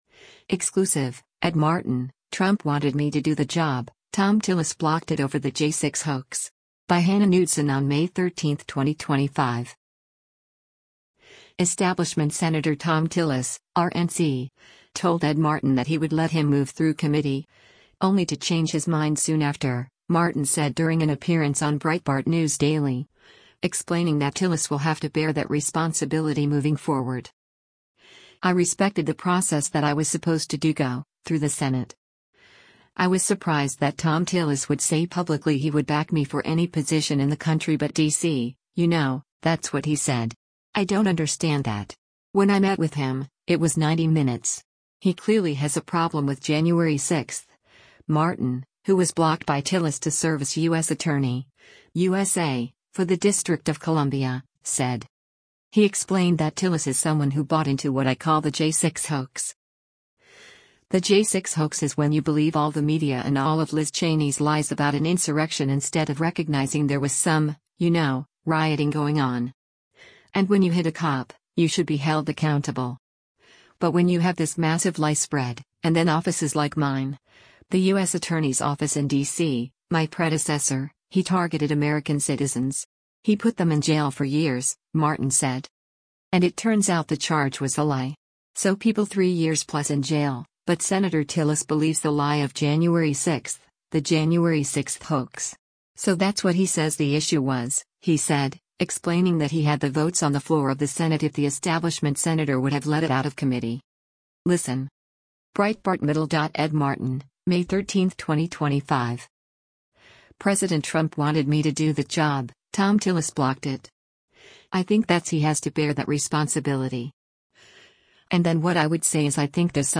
Establishment Sen. Thom Tillis (R-NC) told Ed Martin that he would let him move through committee, only to change his mind soon after, Martin said during an appearance on Breitbart News Daily, explaining that Tillis will have to “bear that responsibility” moving forward.
Breitbart News Daily airs on SiriusXM Patriot 125 from 6:00 a.m. to 9:00 a.m. Eastern.